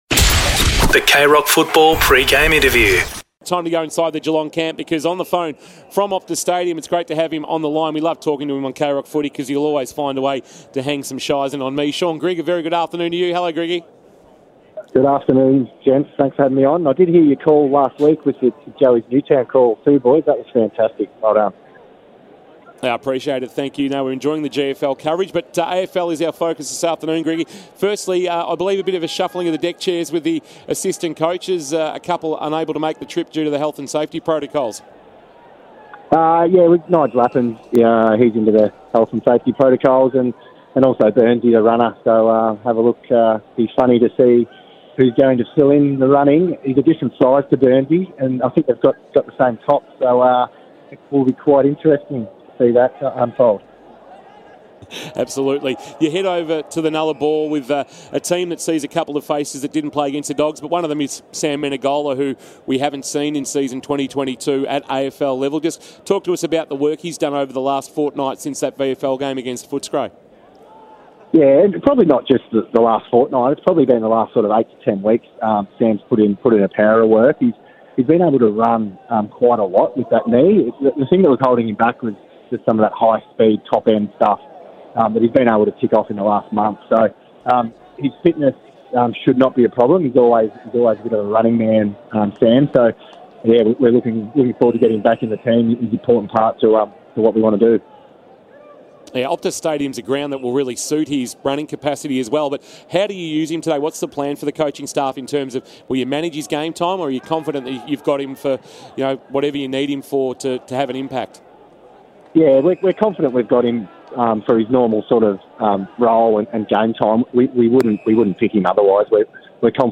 2022 - AFL ROUND 14 - WEST COAST vs. GEELONG: Pre-match Interview